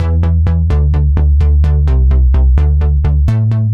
Index of /musicradar/french-house-chillout-samples/128bpm/Instruments
FHC_NippaBass_128-E.wav